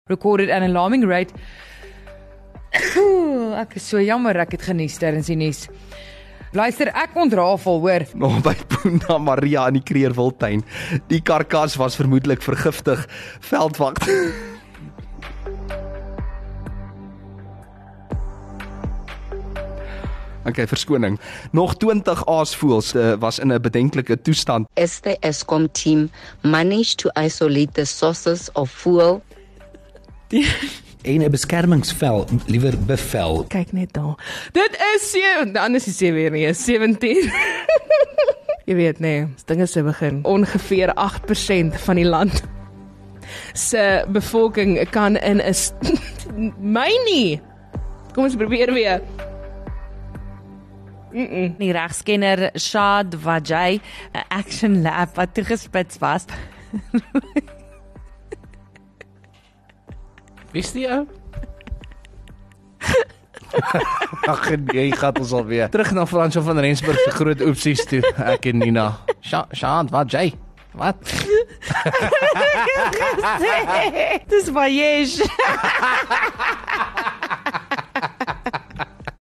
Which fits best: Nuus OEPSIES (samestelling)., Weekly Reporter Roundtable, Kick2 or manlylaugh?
Nuus OEPSIES (samestelling).